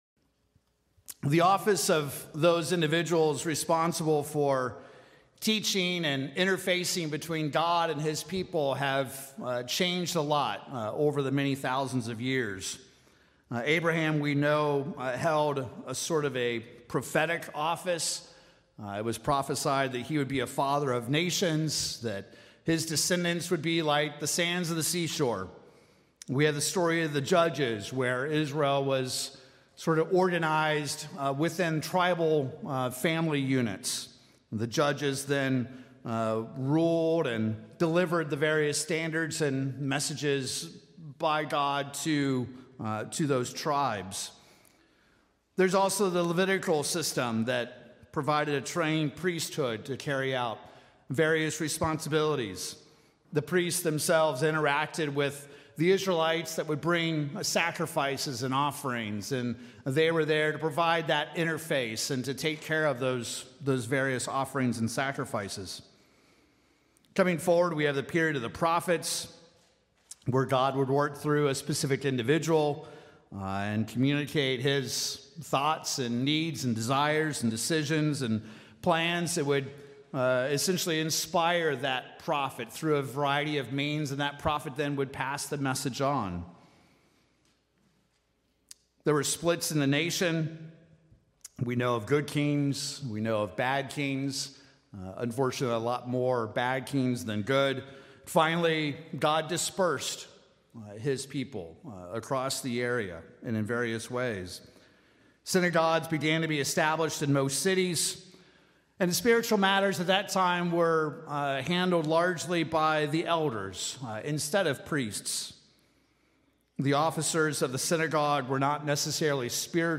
In this sermon message, we will review where the New Testament ministry comes from, its qualifications, and God’s expectation for those men called to this role.